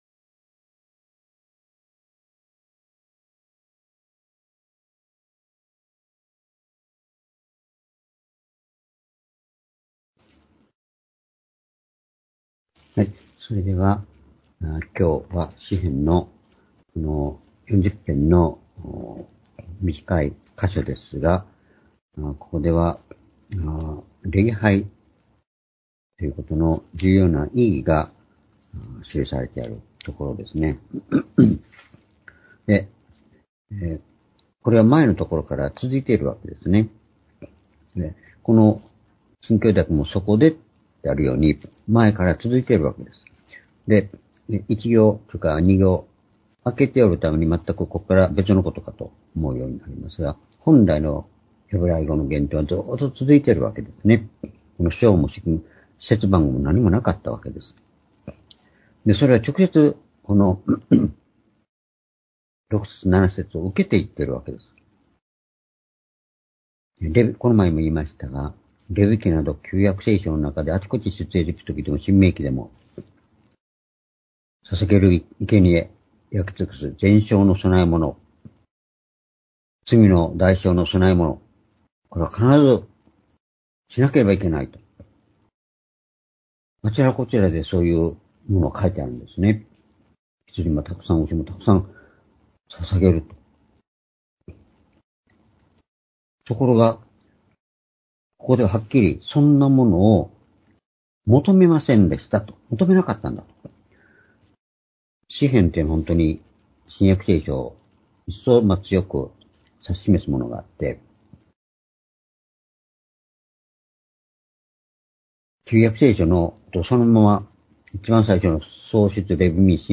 （主日・夕拝）礼拝日時 ２０２４年3月5日（夕拝） 聖書講話箇所 「主の求める礼拝のありかた」 詩編40の8-12 ※視聴できない場合は をクリックしてください。